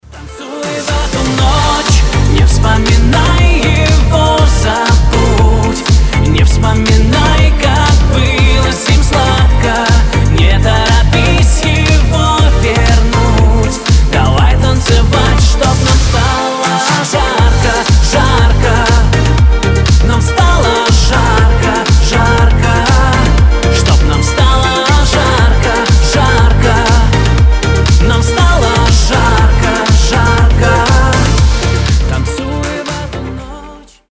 • Качество: 320, Stereo
диско
Synth Pop